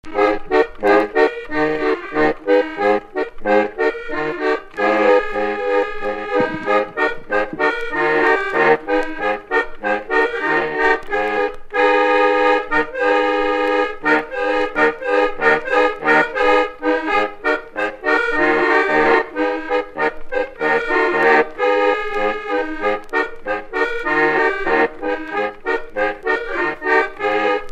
Chants brefs - A danser
danse : polka
Pièce musicale inédite